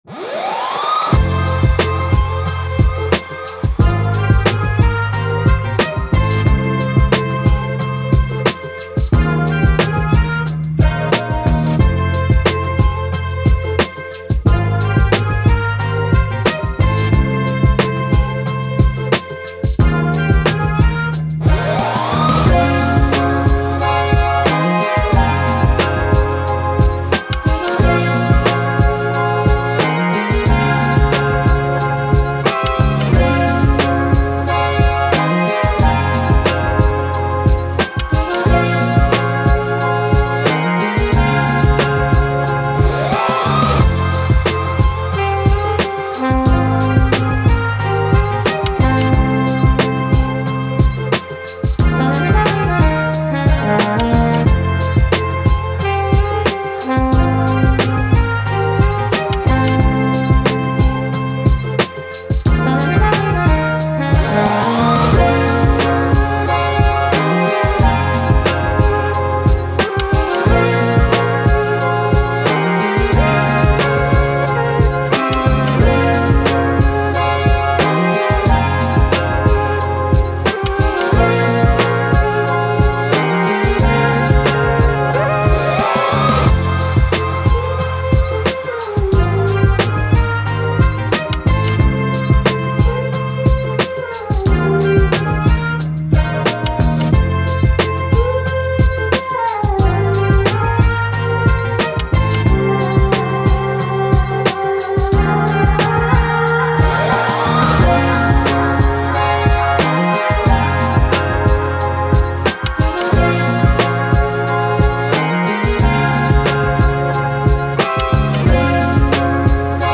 Hip Hop, Boom Bap, Action, Positive, Uplifting